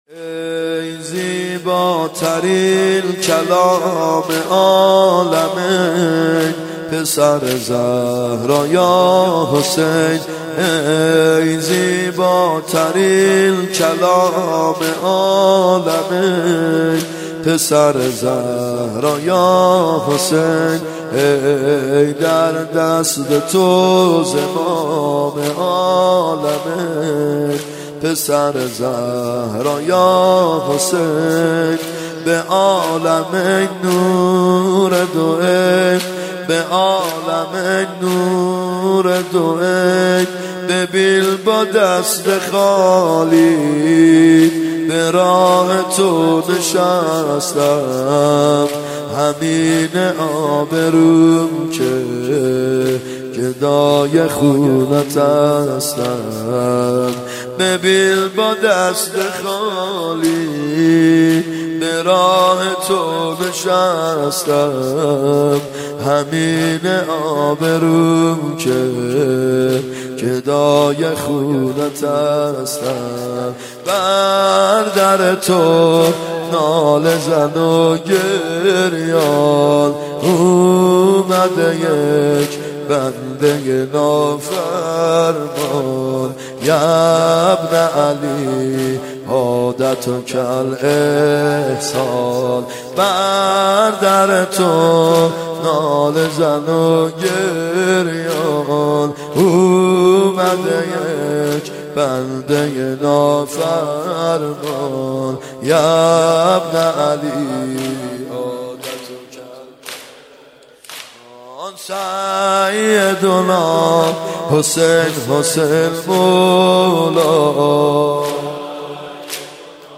محرم 92 شب یازدهم زمینه
محرم 92 ( هیأت یامهدی عج)